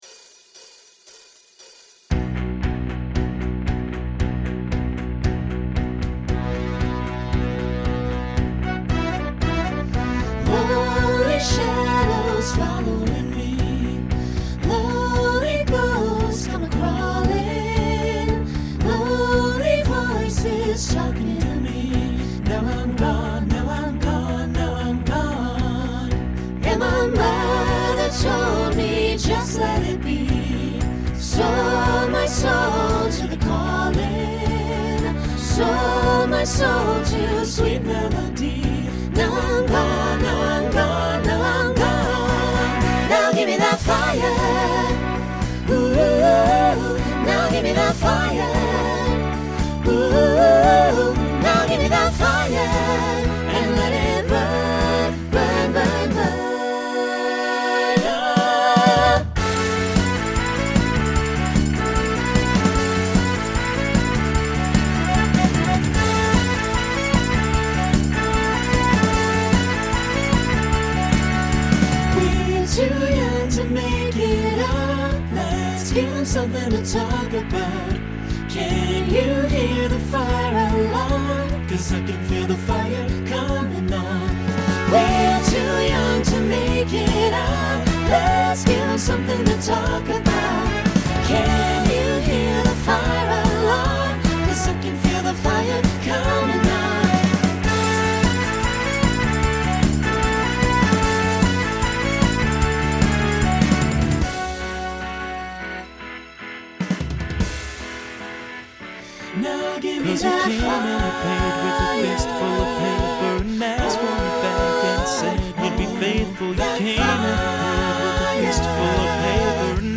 Genre Pop/Dance , Rock Instrumental combo
Opener Voicing SATB